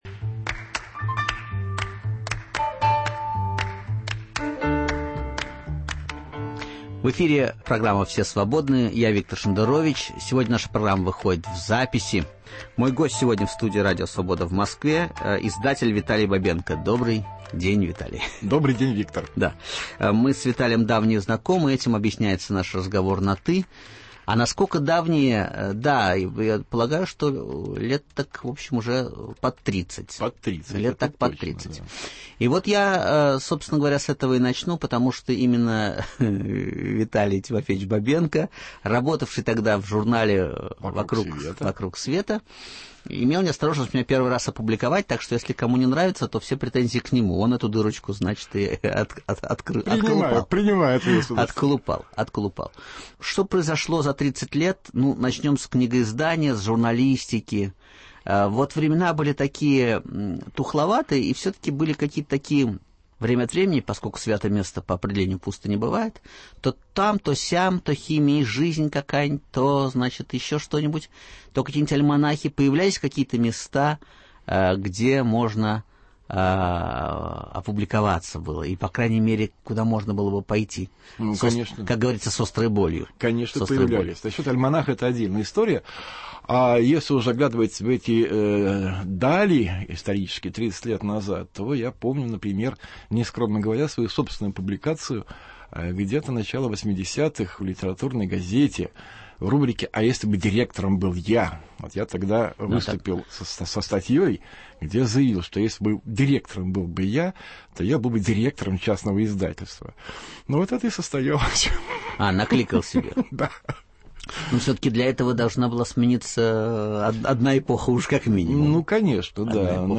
В гостях у Виктора Шендеровича - писатель и издатель Виталий Бабенко.